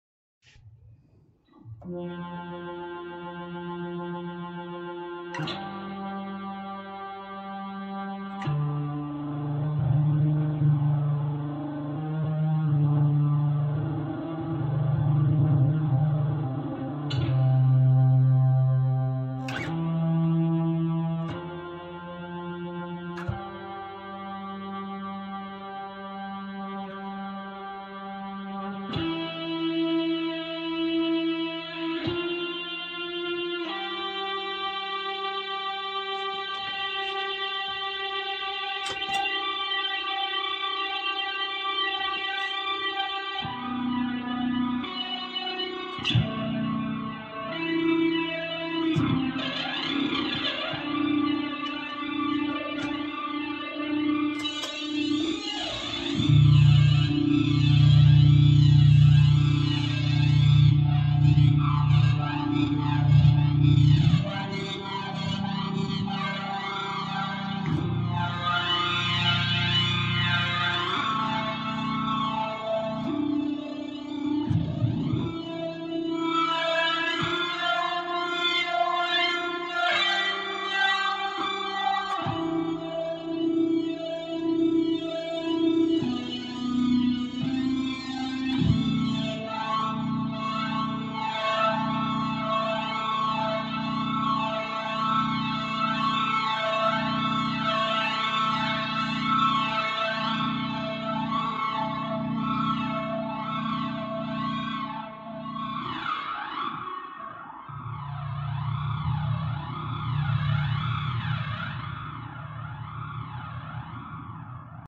demo de un poco de como funciona el C4 de Source audio en mi pedalera.